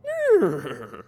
neigh.ogg